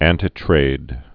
(ăntĭ-trād)